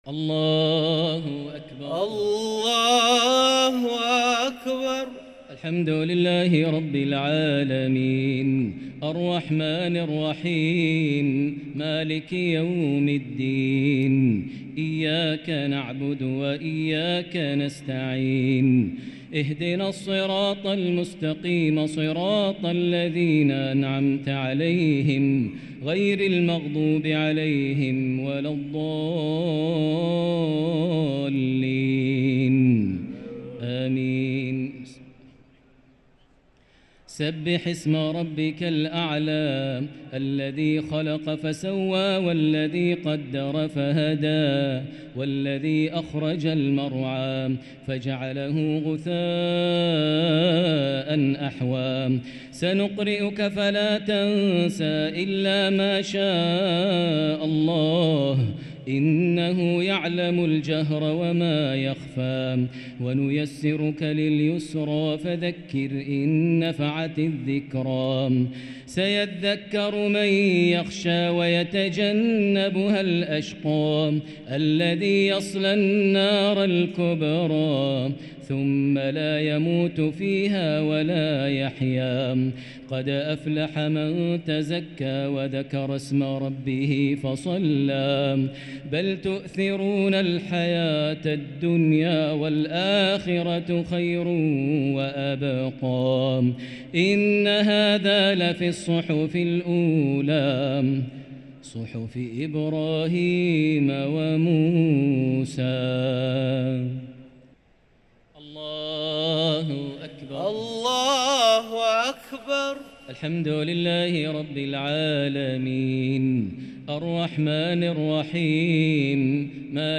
صلاة التراويح ليلة 2 رمضان 1444 للقارئ ماهر المعيقلي - التسليمتان الأخيرتان صلاة التراويح